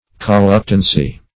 Search Result for " colluctancy" : The Collaborative International Dictionary of English v.0.48: Colluctancy \Col*luc"tan*cy\, n. [L. colluctari to struggle with.]